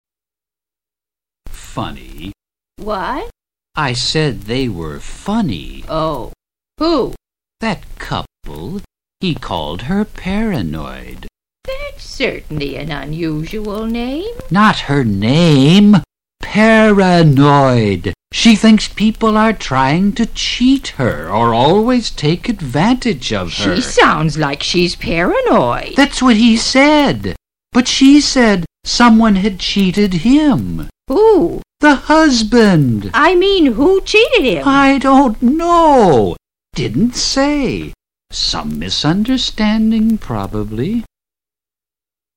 Conversaciones en un Autobús
Old Woman and Old Man
In this first step, you will listen through this conversation between an old woman and an old man onboard the bus.
conversation06.mp3